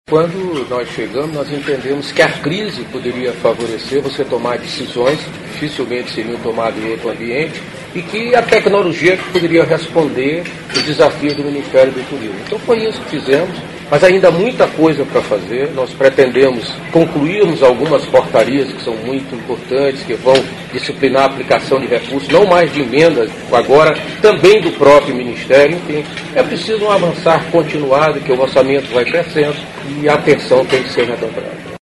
aqui e ouça declaração do ministro Gastão Vieira sobre o empenho do MTur para aperfeiçoar mecanismos de controle.